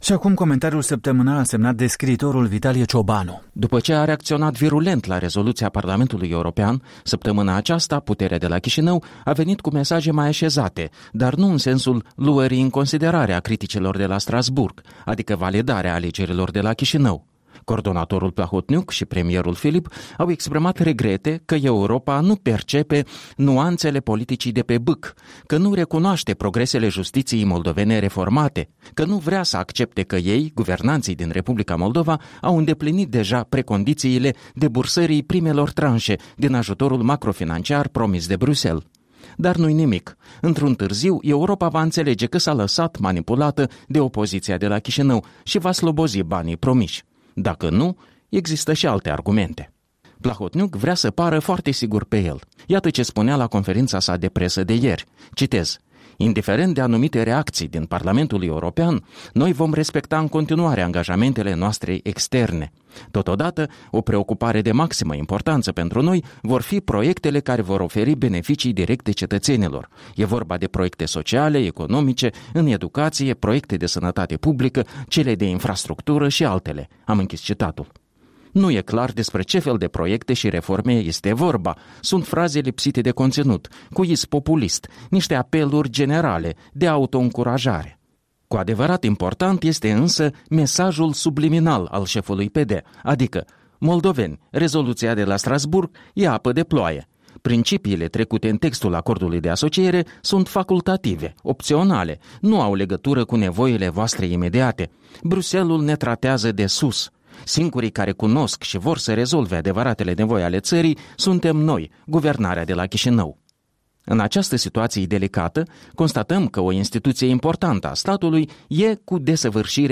Un comentariu săptămînal